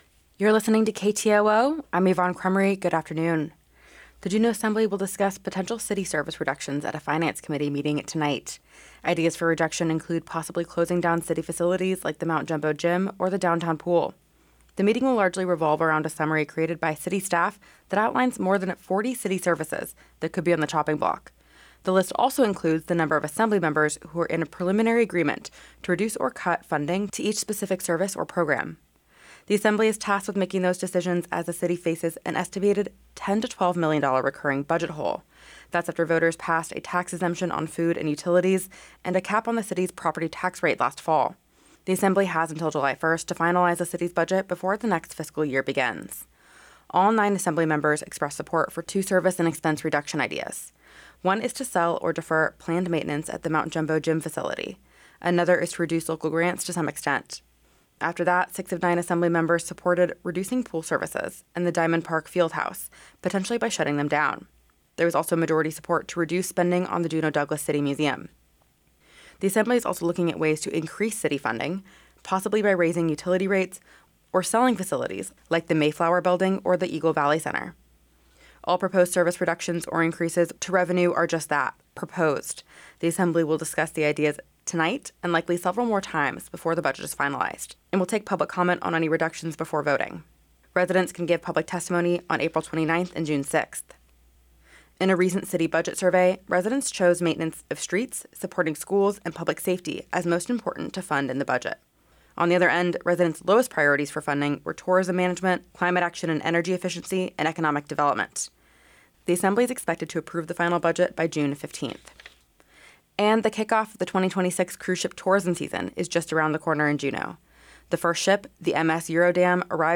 Newscast - Wednesday, April 22, 2026